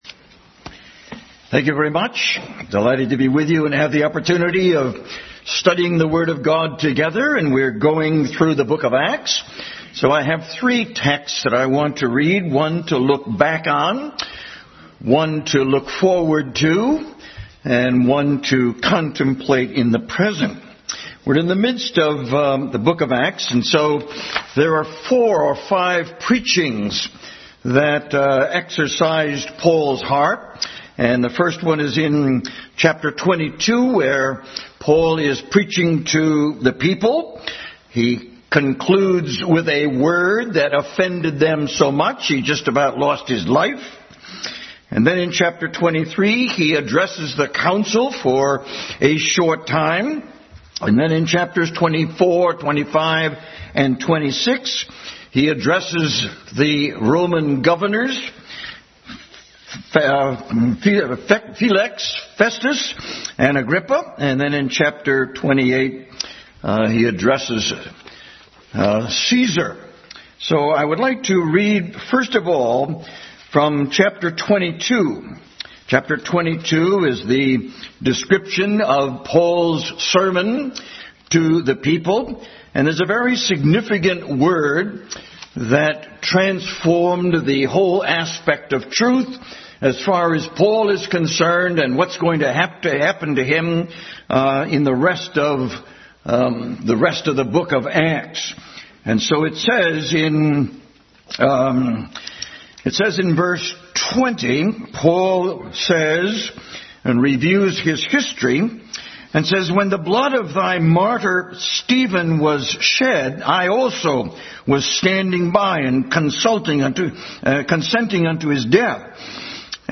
Bible Text: Acts 23:11-35, Acts 22:20-23, Acts 24:27 | Study in the book of Acts adult Sunday School Class.
Acts 24:27 Service Type: Sunday School Bible Text